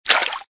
TT_splash2.ogg